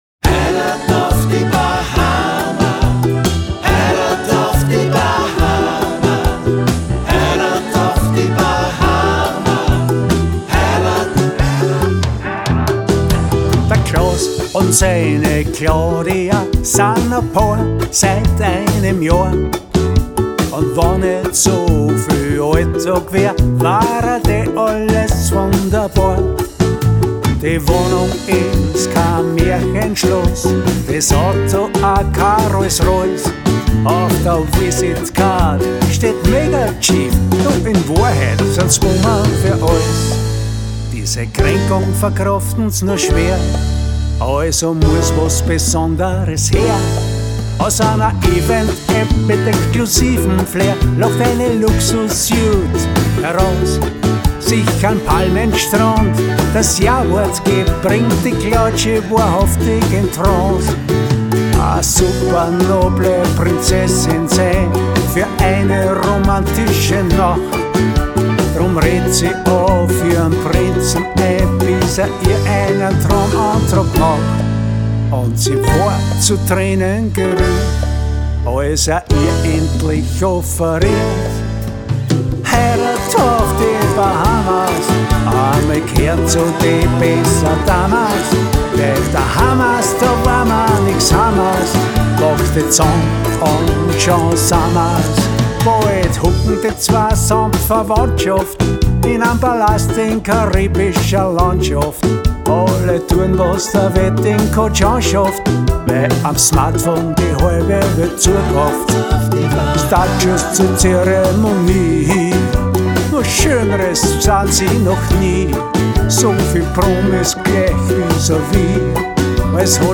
dr,syn
uke, chorals
voc,g;T&M